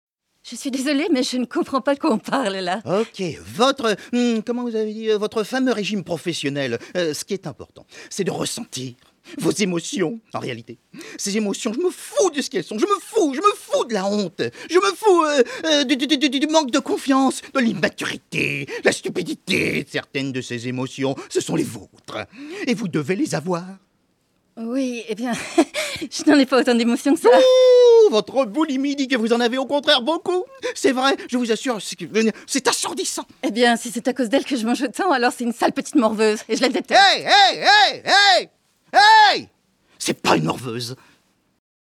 VOIX DOUBLAGE – TELEFILM « Mon mariage surprise » (la thérapeute)